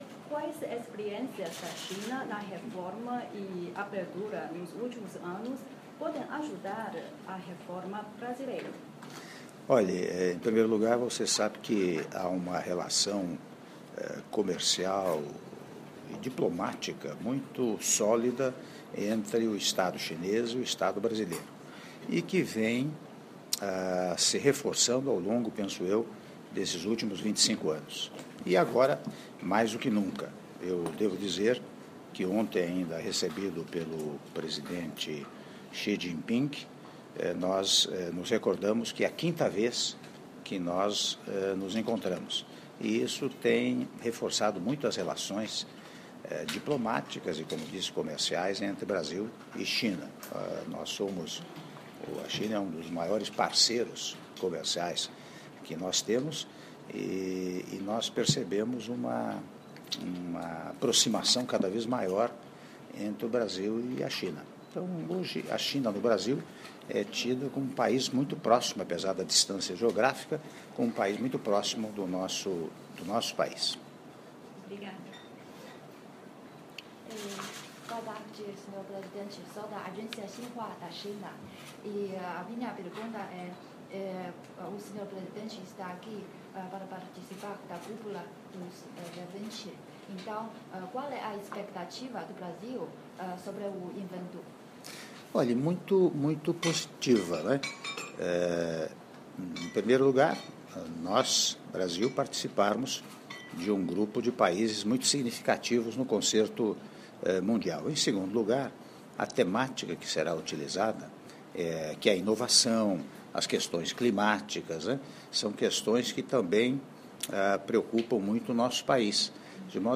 Áudio da entrevista coletiva concedida pelo Senhor presidente da República, Michel Temer, em Hangzhou, China (16min34s)